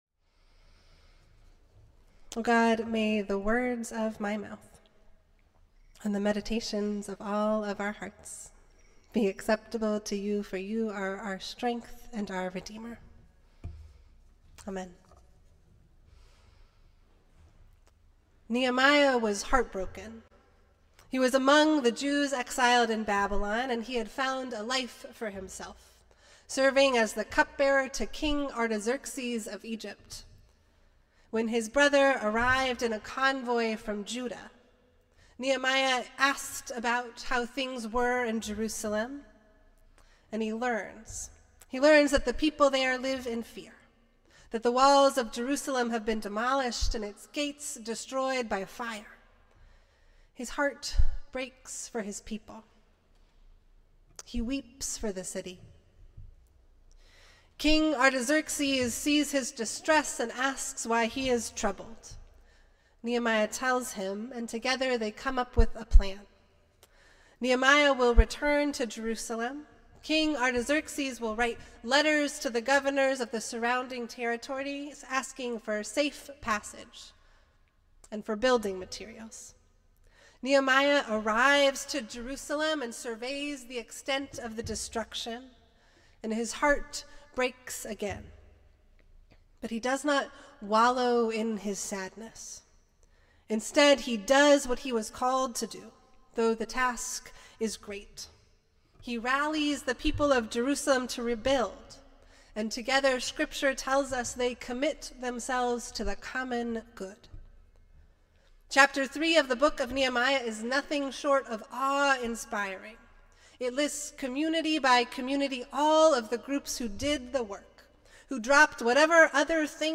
Festival Worship - Eighteenth Sunday after Pentecost